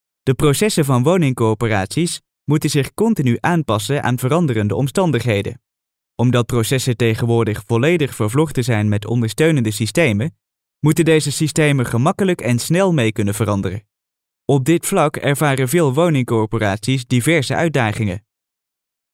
Grabaciones en nuestro estudio de sonido asociado de Holanda.
Locutores holandeses